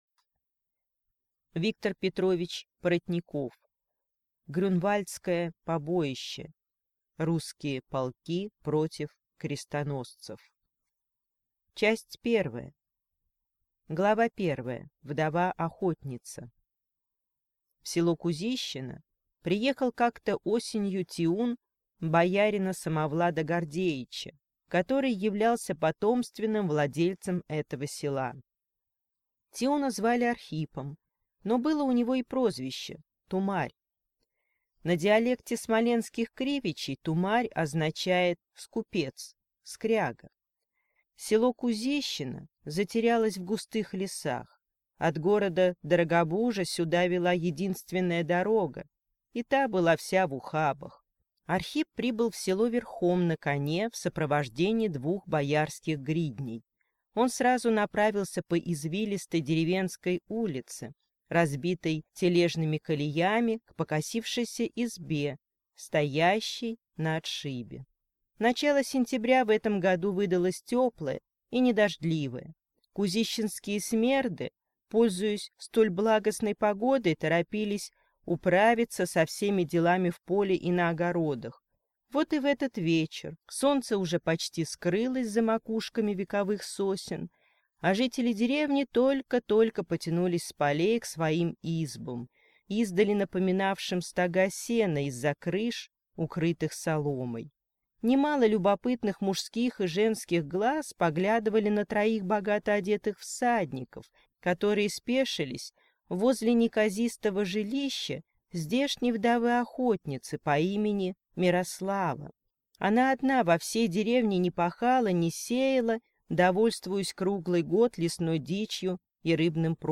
Аудиокнига Грюнвальдское побоище. Русские полки против крестоносцев | Библиотека аудиокниг